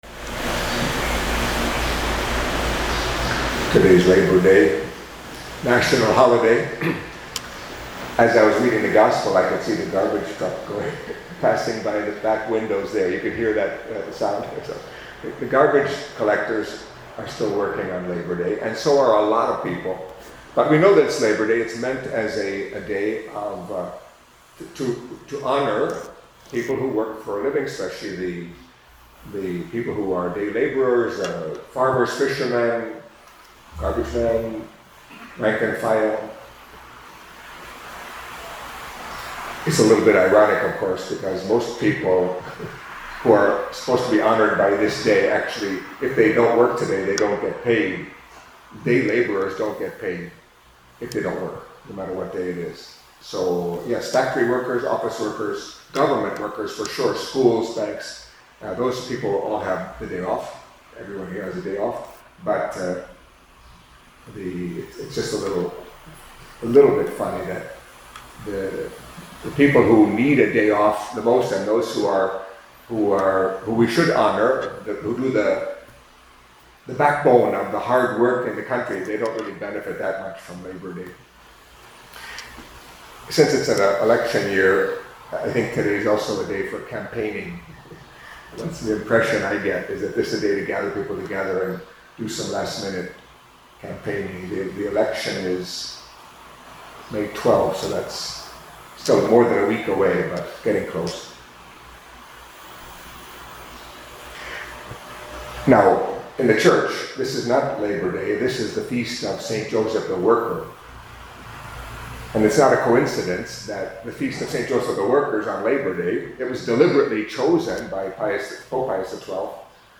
Catholic Mass homily for Thursday of the Second Week of Easter